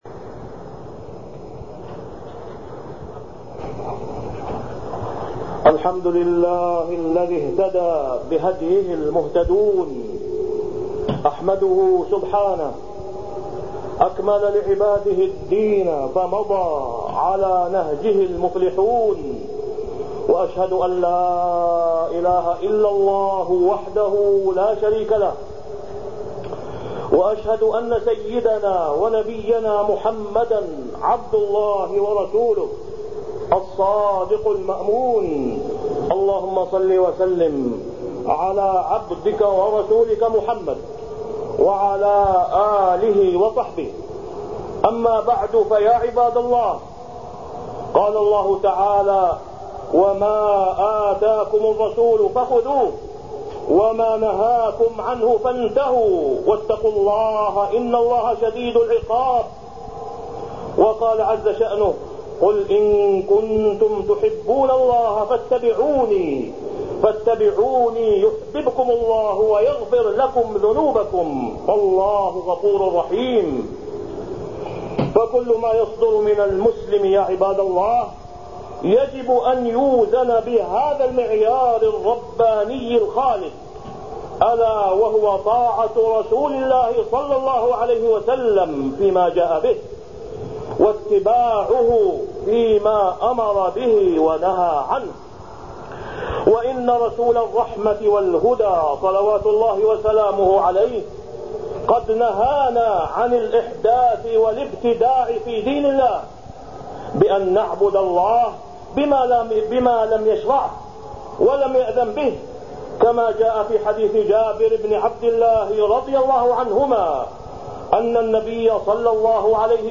تاريخ النشر ١٤ شعبان ١٤٢٤ هـ المكان: المسجد الحرام الشيخ: فضيلة الشيخ د. أسامة بن عبدالله خياط فضيلة الشيخ د. أسامة بن عبدالله خياط كمال الدين وتمامه